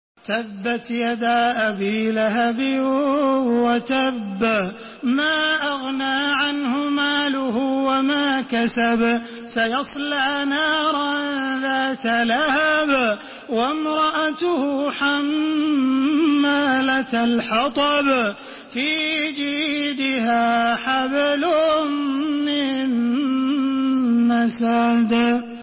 Listen and download the full recitation in MP3 format via direct and fast links in multiple qualities to your mobile phone.
تراويح الحرم المكي 1432
مرتل